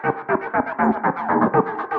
描述：一种奇怪的节奏声。
我认为这很可能是在Ableton Live中制作的。
标签： 奇怪 延迟 回声 合成的 合成的 有节奏
声道立体声